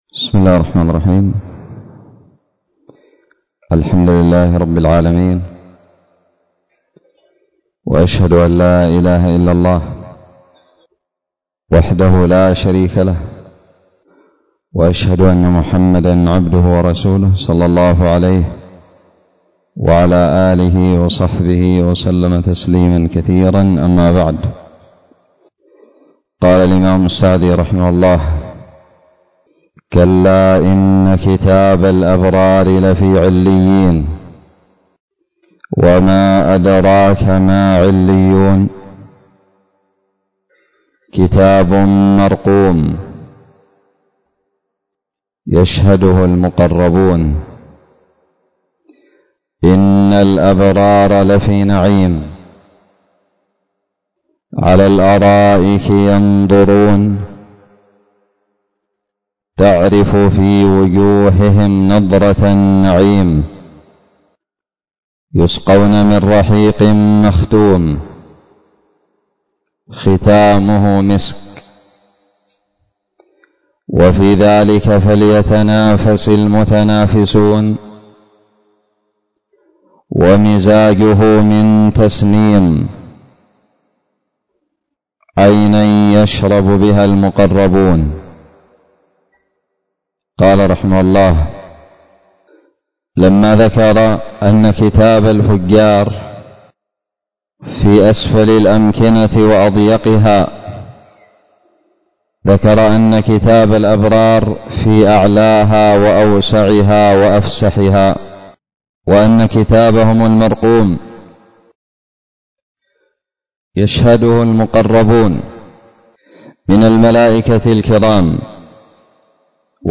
الدرس الثالث من تفسير سورة المطففين
ألقيت بدار الحديث السلفية للعلوم الشرعية بالضالع